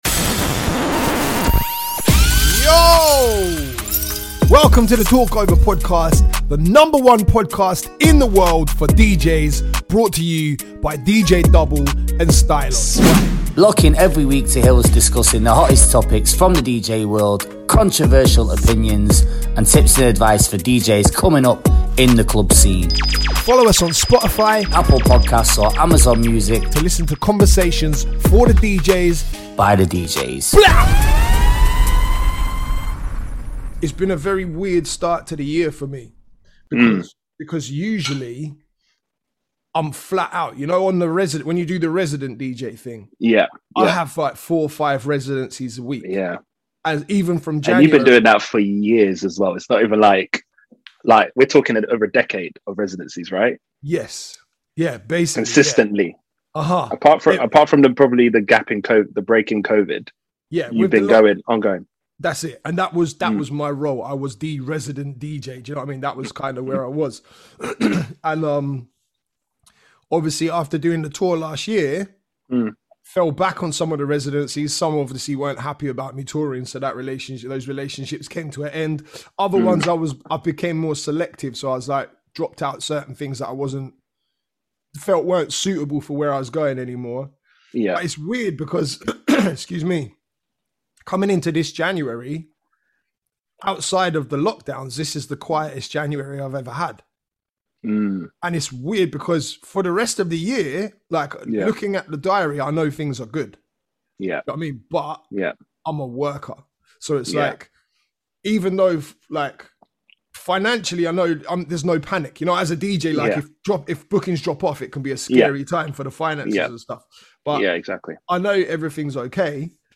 two DJs from the UK